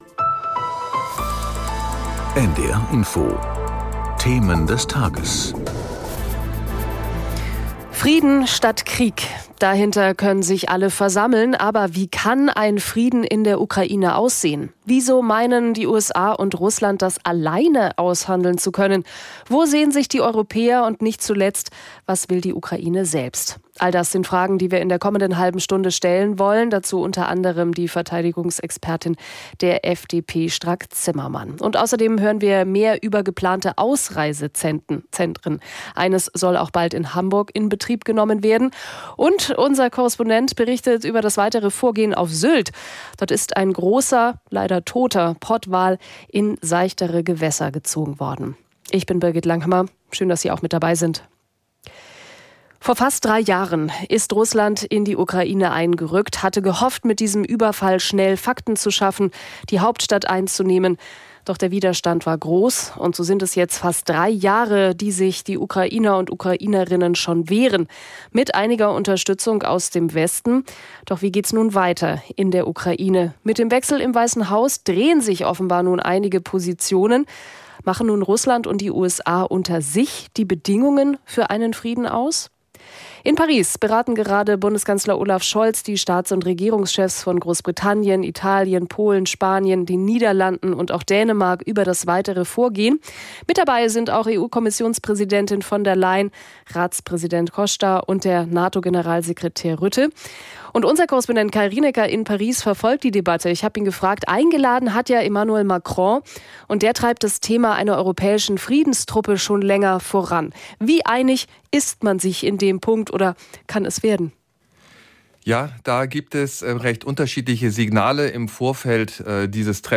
Das Topthema wird aus verschiedenen Perspektiven beleuchtet. In Interviews mit Korrespondenten, Experten oder Politikern.